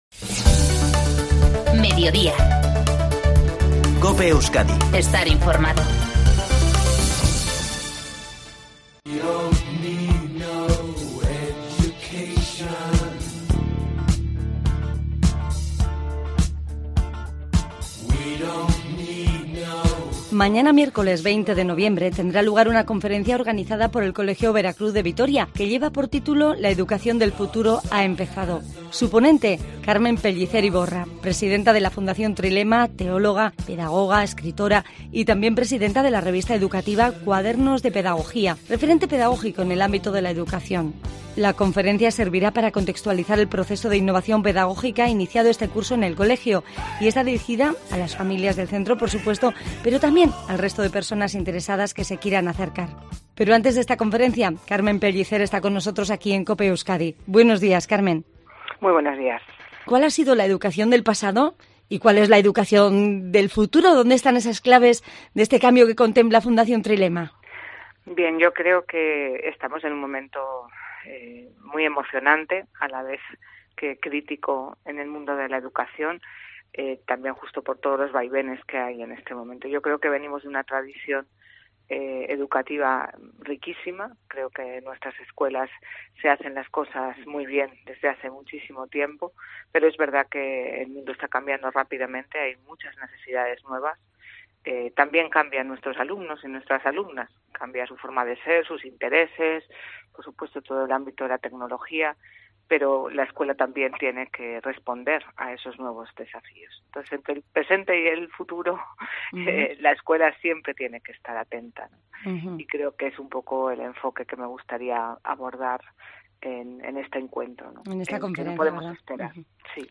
Escucha su entrevista en COPE Euskadi